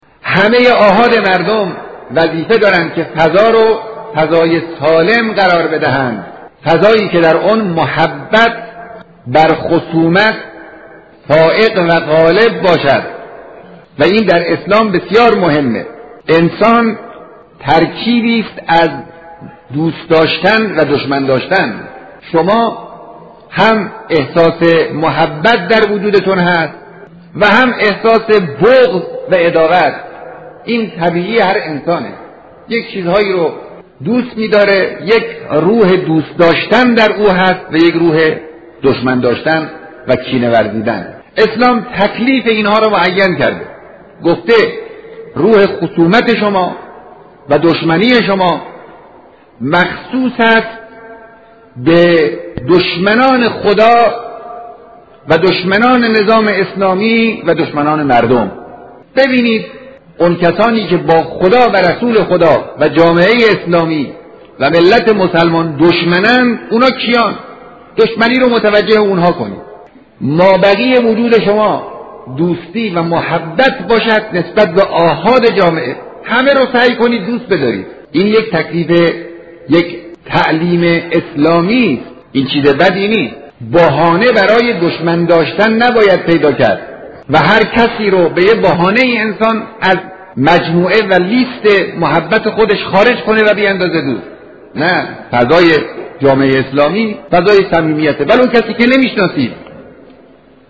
صوت در خطبه های نماز جمعه سال 1368 تهران